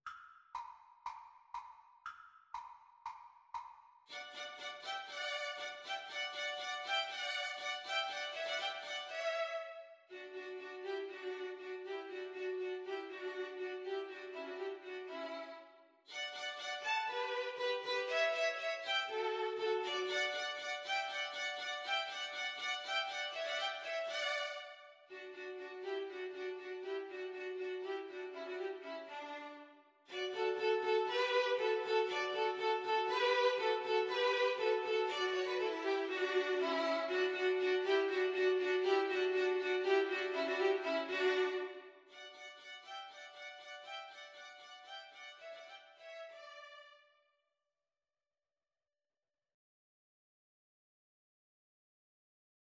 Allegro (View more music marked Allegro)
String trio  (View more Easy String trio Music)